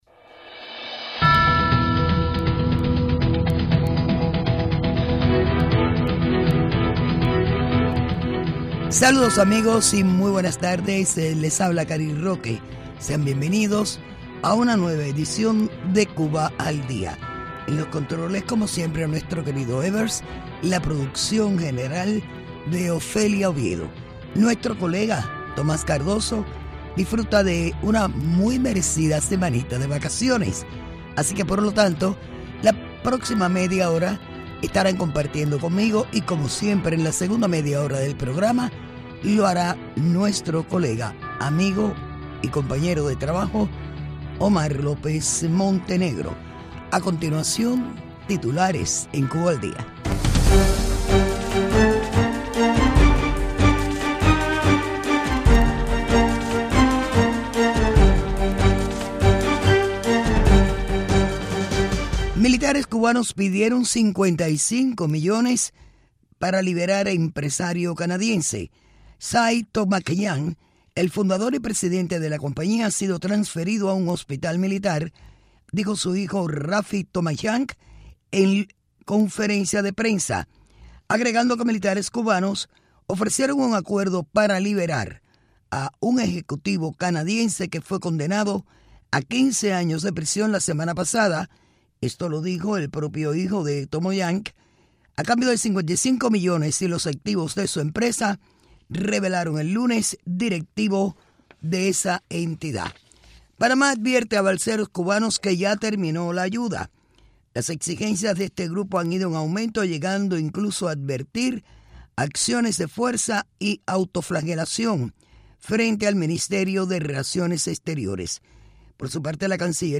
Entevistas